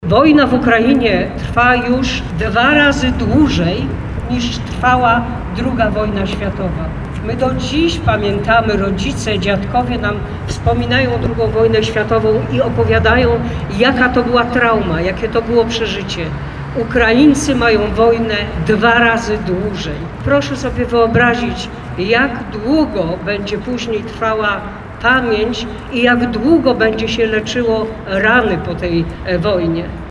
Bielszczanie spotkali się na pl. Chrobrego, aby pomodlić się za pokój w zaatakowanym kraju.